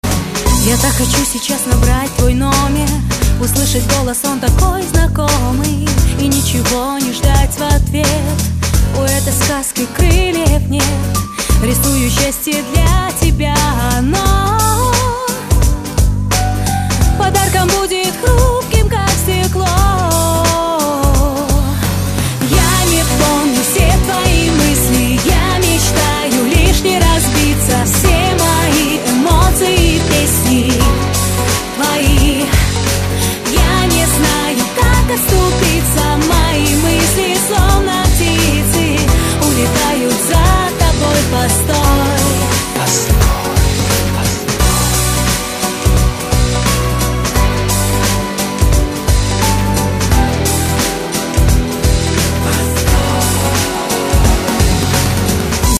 • Качество: 128, Stereo
поп
женский вокал